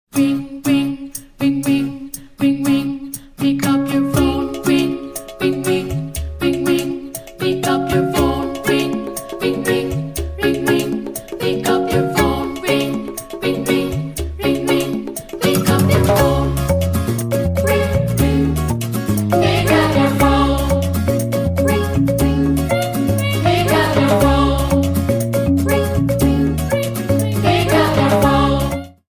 Category: Funny Ringtones